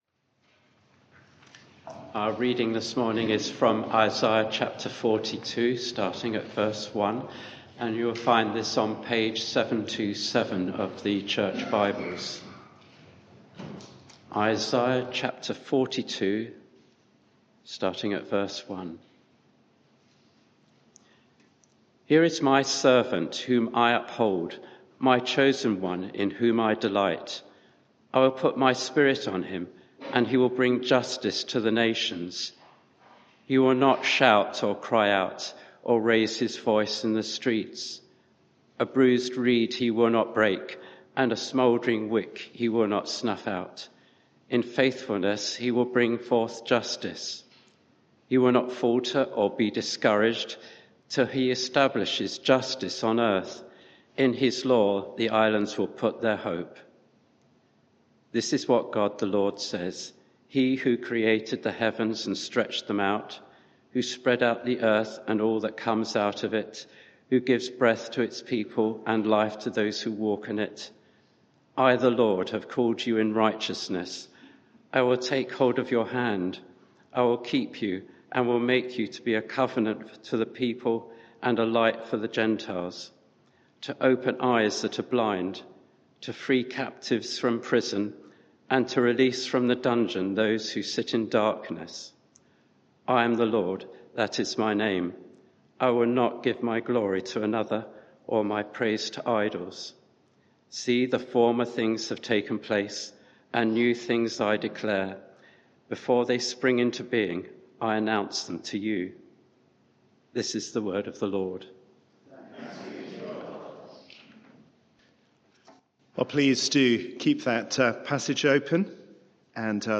Media for 11am Service on Sun 27th Feb 2022 11:00 Speaker
Series: The Servant King Theme: The Servant of God Sermon